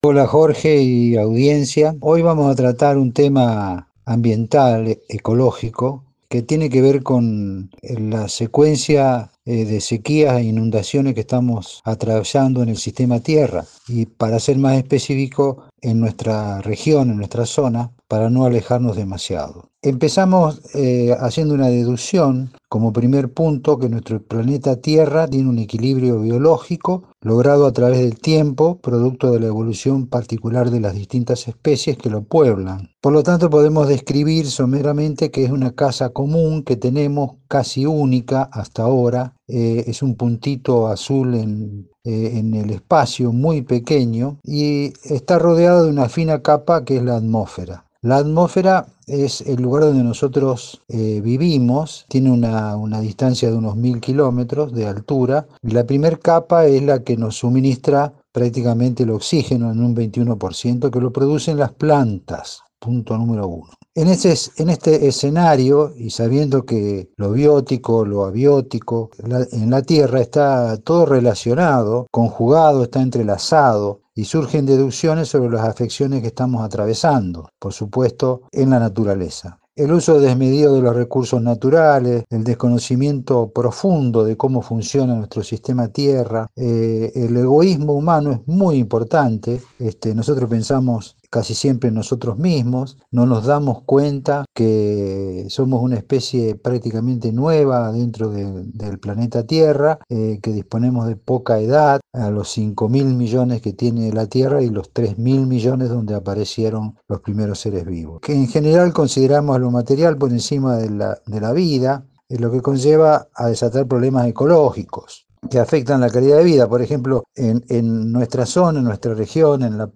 En una entrevista  con nuestra radio